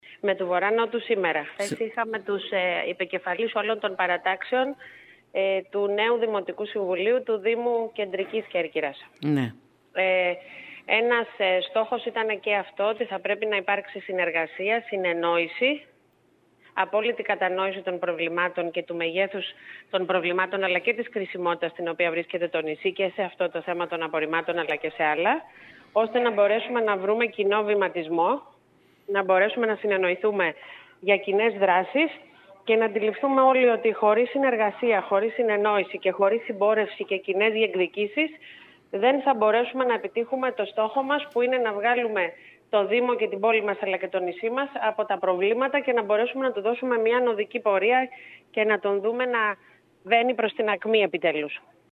Αμέσως μετά το τέλος της συνάντησης η νέα δήμαρχος κ. Μερόπη Υδραίου , έκανε την ακόλουθη δήλωση: